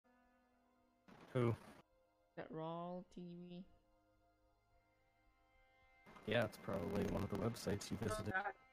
Shure SM7B (Static).mp3
That sounds more like the noises you get when capacitors dry out - but that wouldn't happen at the same time.
It sounds like digital artefacts to me and I'm going to take a stab at your streaming software.